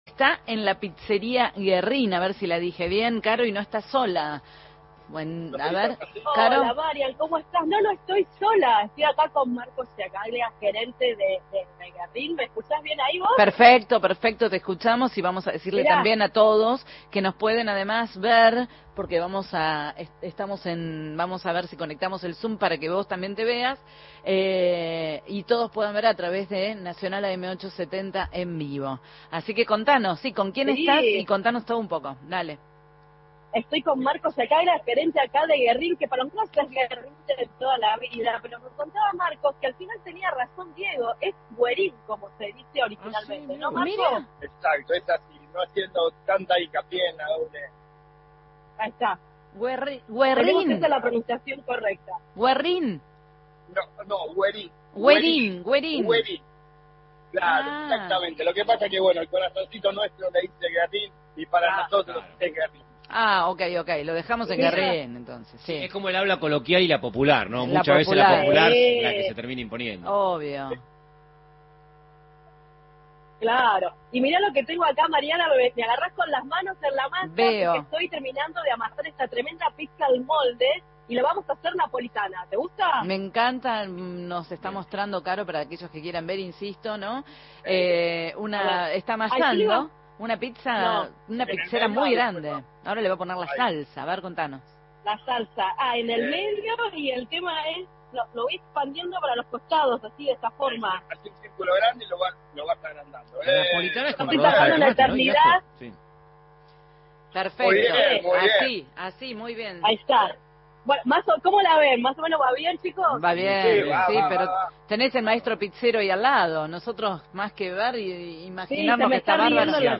ENTREVISTA EN NUNCA ES TARDE